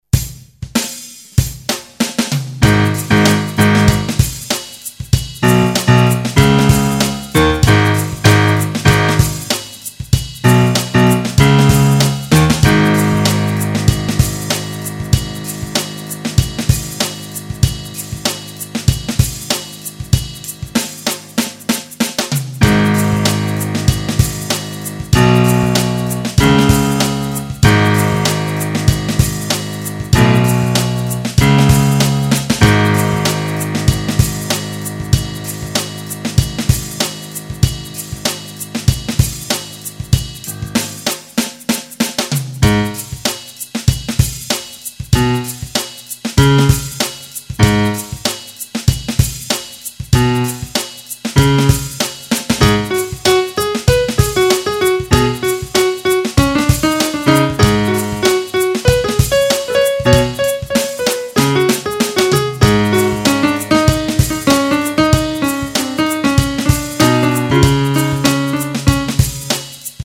Here's an example of the variation between the 'real' bassline and the bassline I can actually solo over. It then develops at around 50 seconds into a go at getting a bit tighter on the solos. There are quite a few stuffups here, but there are also a few ideas that almost get out that I wanted to note - particularly that last 'three note' one.
piano.mp3